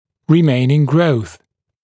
[rɪ’meɪnɪŋ grəuθ][ри’мэйнин гроус]остаточный рост, остаток потенциала роста